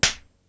wzzz_voice_beat.wav